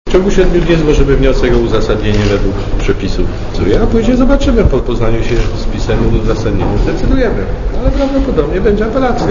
Adwokat dla Radia Zet